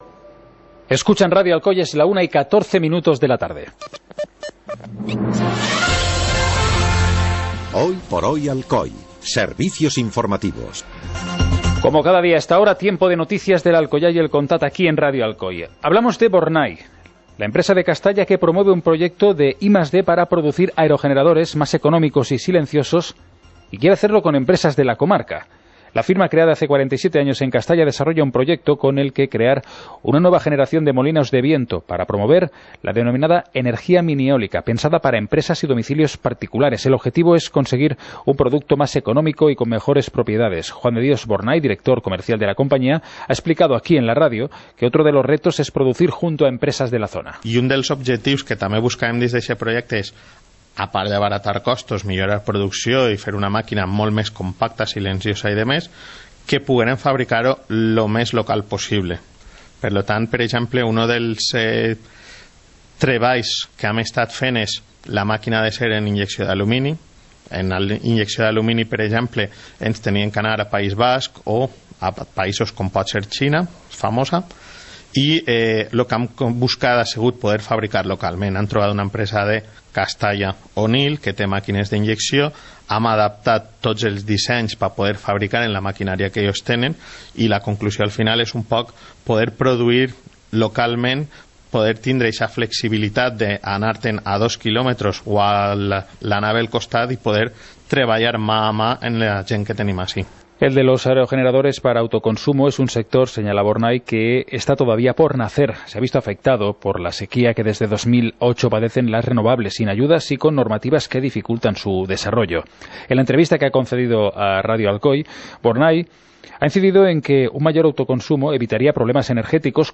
Informativo comarcal - martes, 17 de octubre de 2017